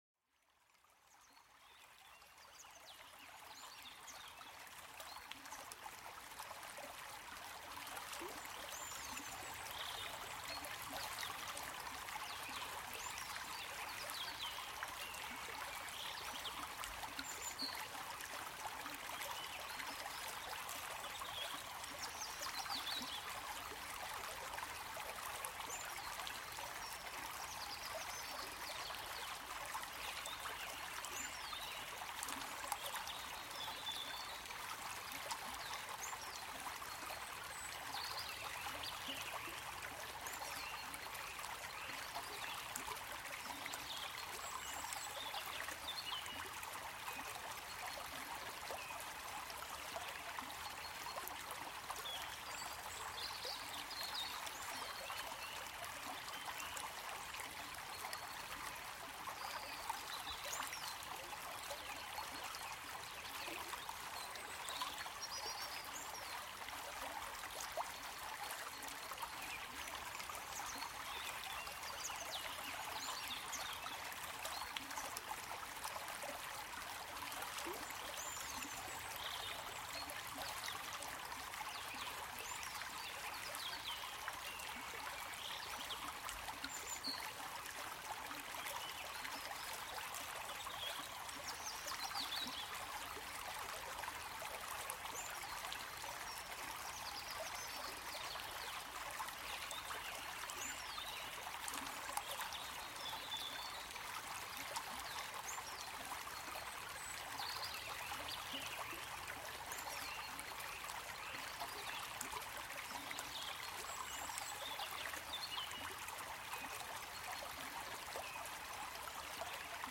Plongez dans cet épisode où le son apaisant de l'eau d'une rivière vous transporte vers une relaxation profonde. Laissez-vous bercer par le murmure de l'eau qui coule, idéal pour apaiser l'esprit et réduire le stress.